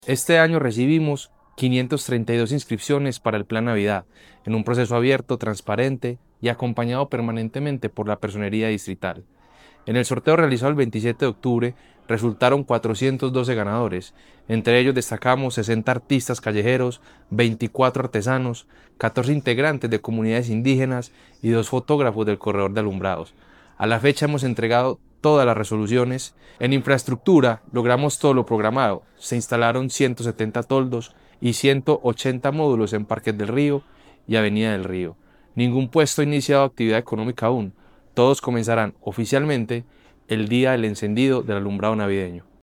Declaraciones del subsecretario de Espacio Público, David Ramirez
Declaraciones-del-subsecretario-de-Espacio-Publico-David-Ramirez.mp3